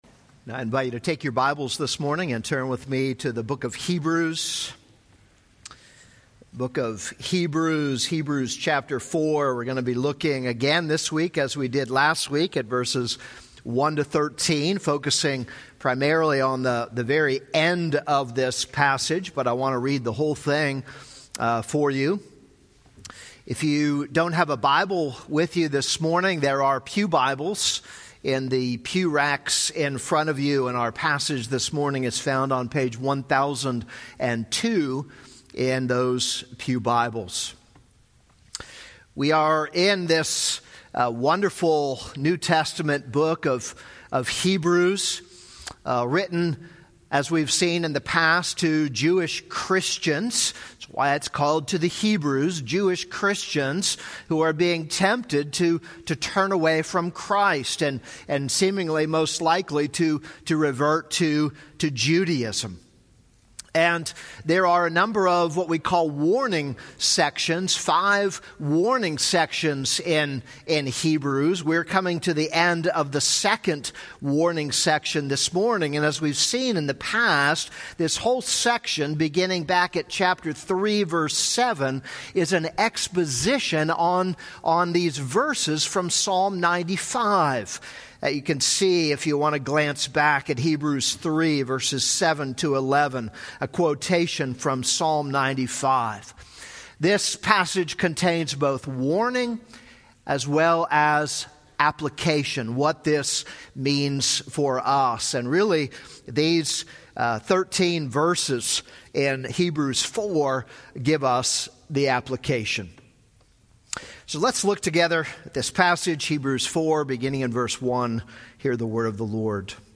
This is a sermon on Hebrews 4:1-13.